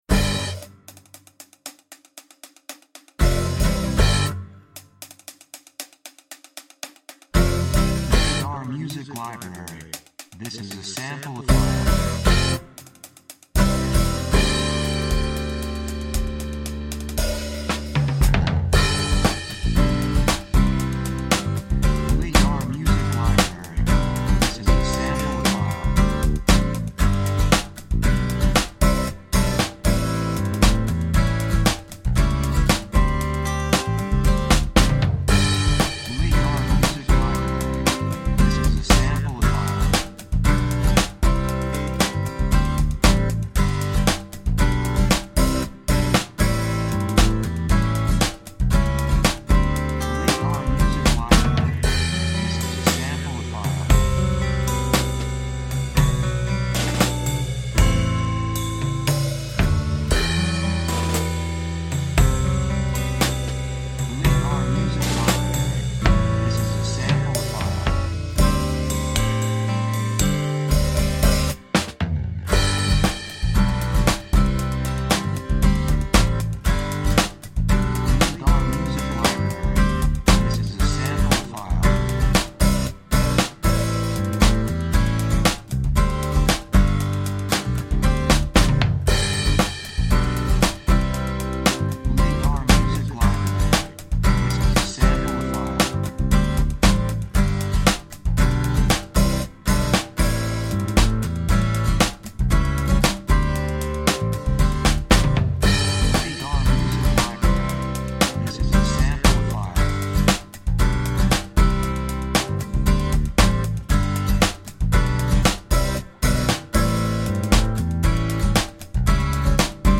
雰囲気幸せ, 穏やか, 高揚感, 感情的
楽器アコースティックギター, ピアノ
サブジャンルフォークポップ
テンポやや速い